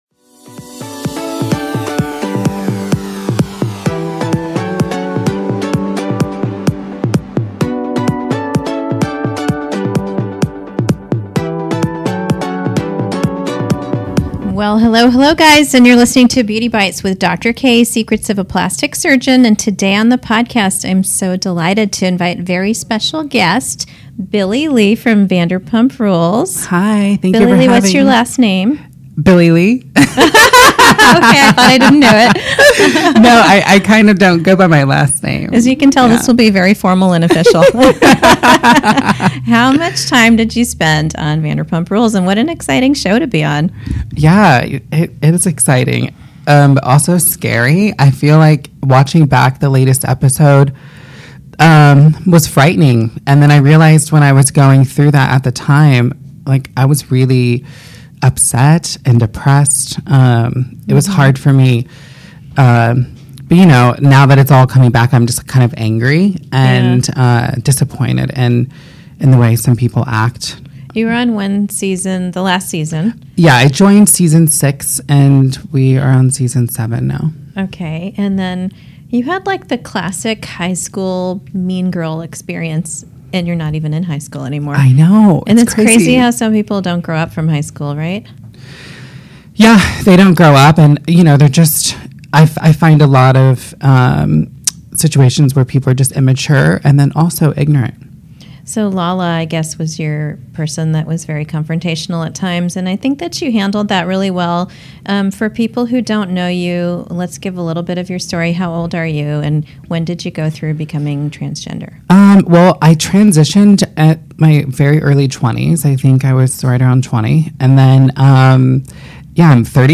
We talk about the transition process, makeup tricks, injectables, and more during the podcast. I'm so happy to have met Billie and hope you all enjoy this very special interview!